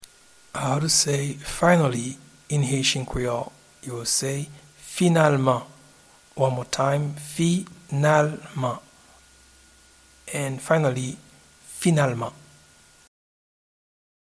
Finally-in-Haitian-Creole-Finalman.mp3